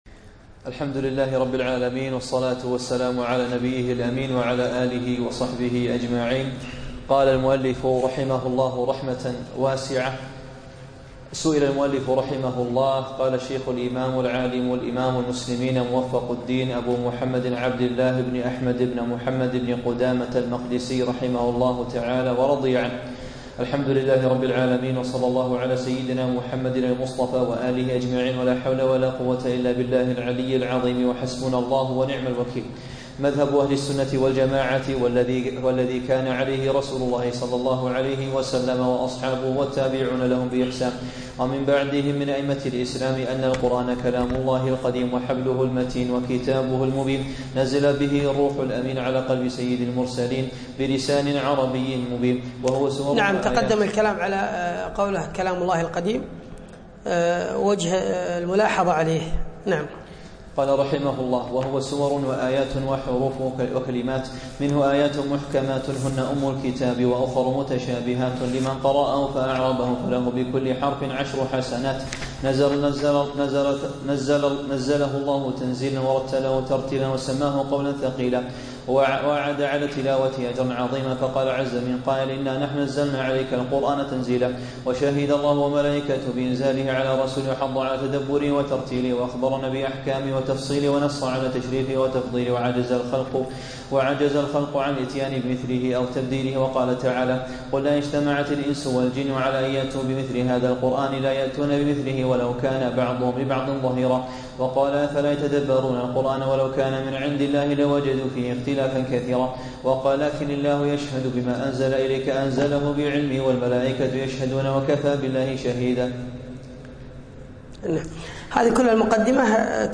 الدرس الأول